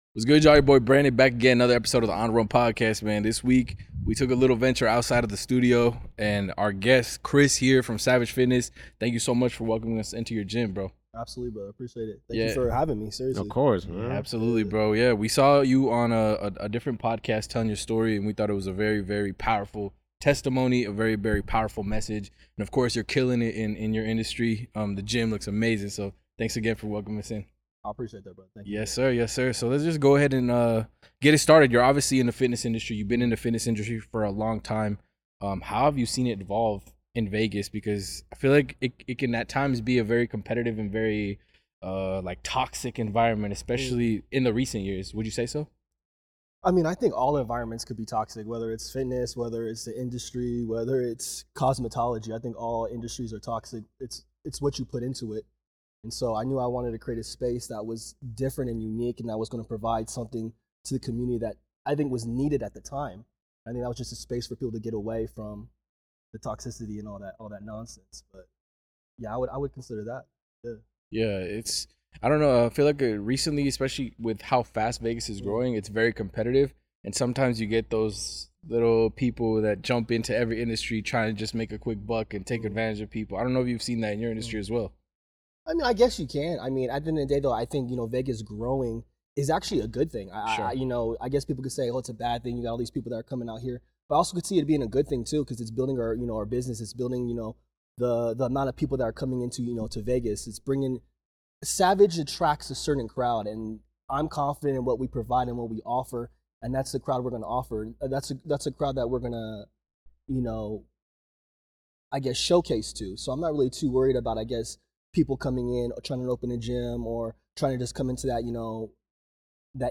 This week, we stepped out of our usual studio to record at one of Las Vegas's most stunning fitness destinations: SVG3 Fitness.